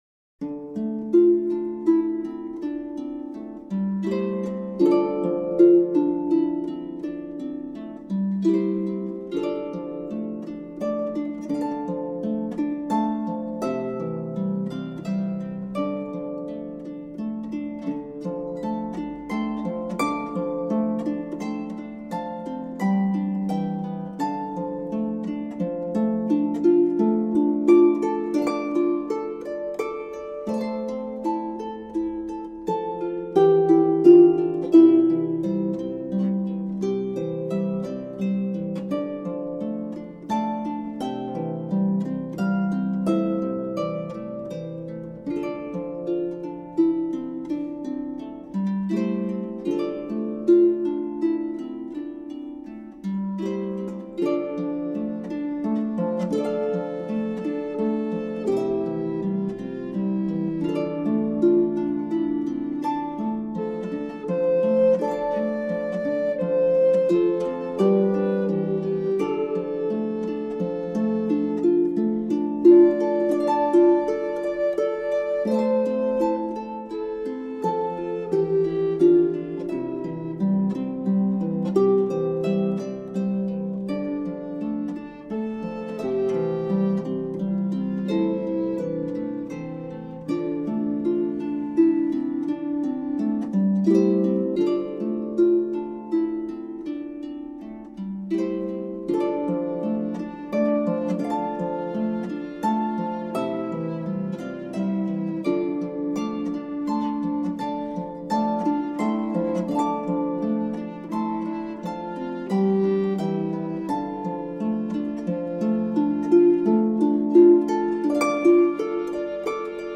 Early music for healing.